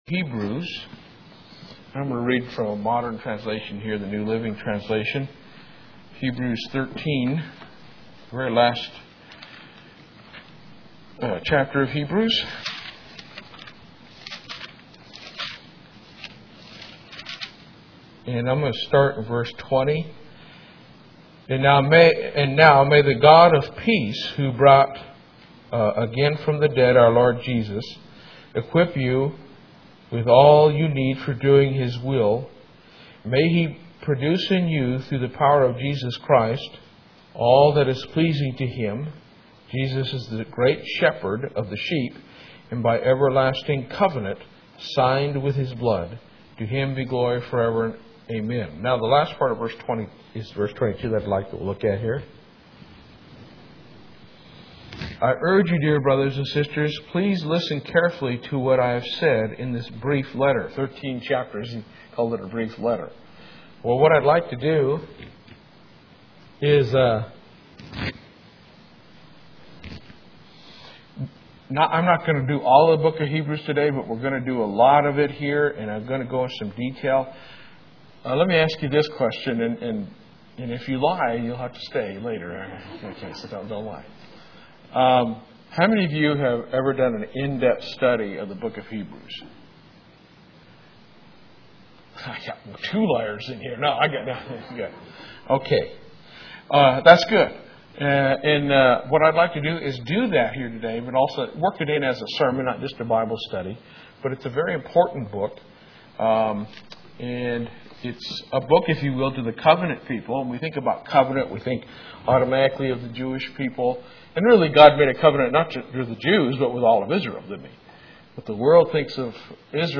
Given in Lubbock, TX
UCG Sermon Studying the bible?